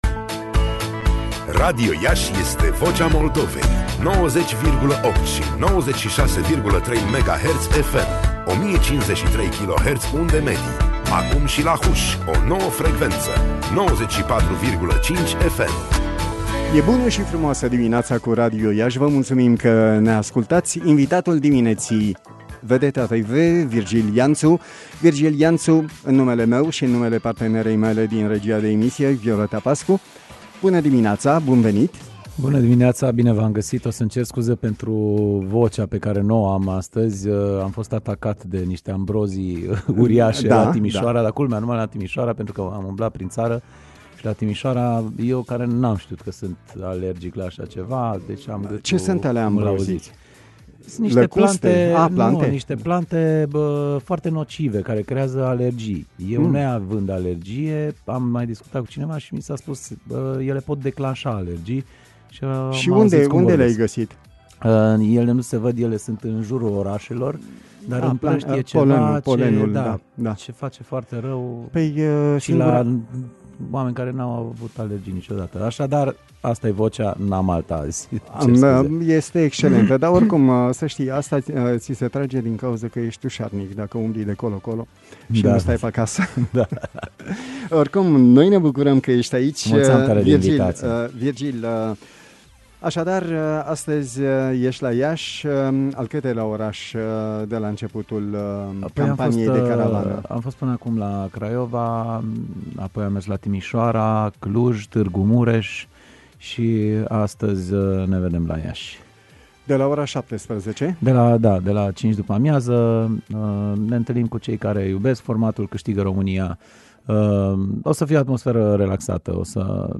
Ascultați-l și bucurați-vă de toate contrastele lui fermecătoare într-o rostire atât de sincera încât reduce la neant însăși ideea de dialog.